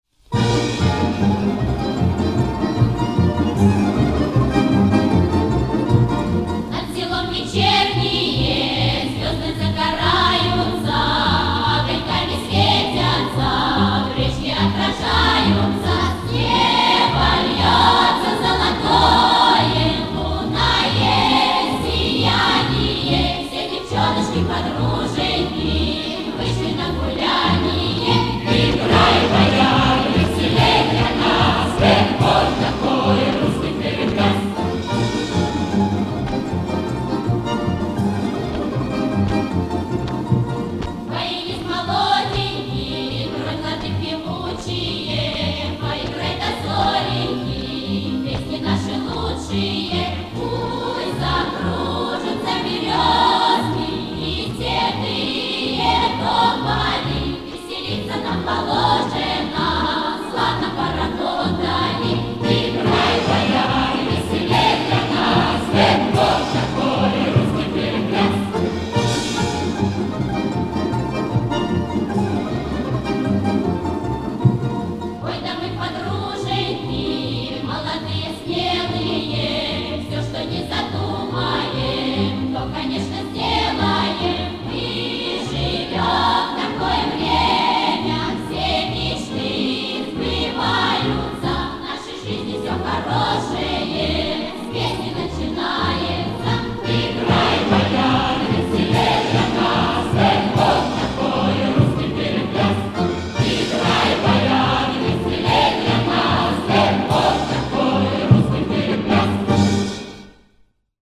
Источник патефонная пластинка